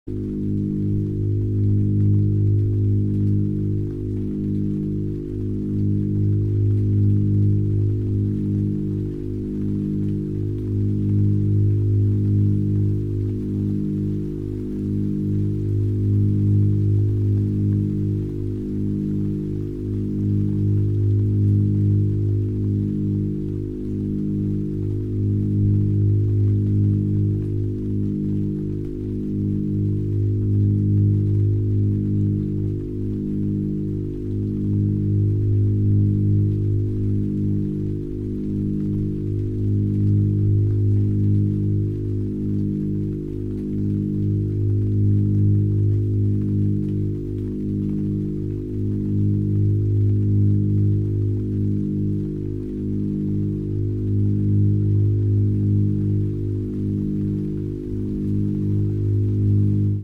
Gamma Binaural Beats for SLEEPING sound effects free download